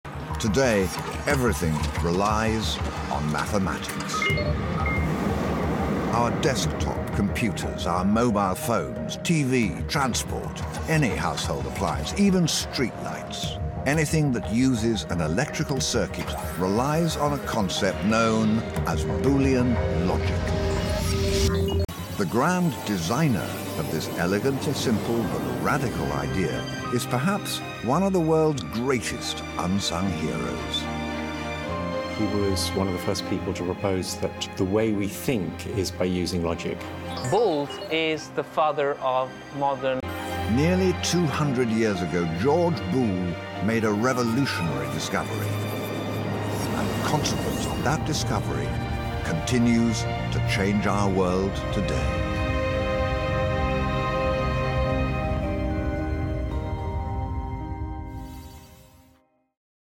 Since the video is not accessible in all countries, here is a small part of the clip which is a trailer for the movie itself. The movie "Genius of George Boole" is an Oxford Film and Television production commissioned by University College Cork.